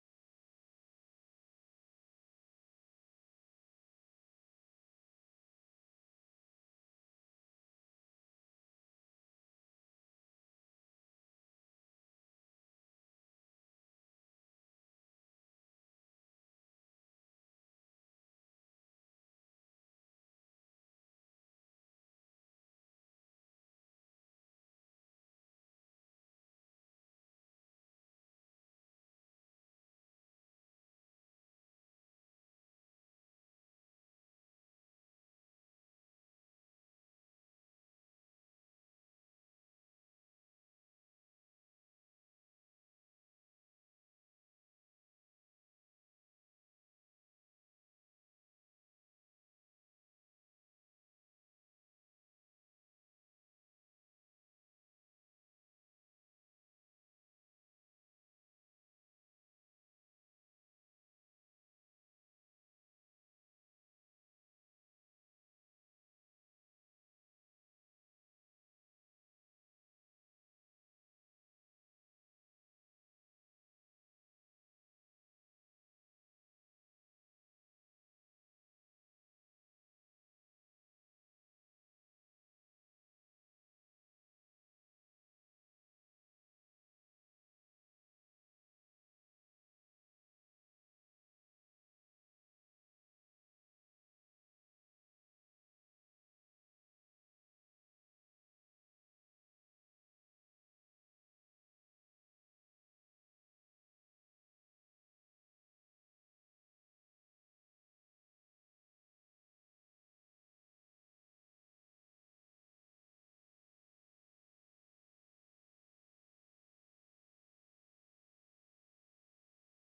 Passage: Matthew 5:13-16 Service Type: Sunday AM « September 22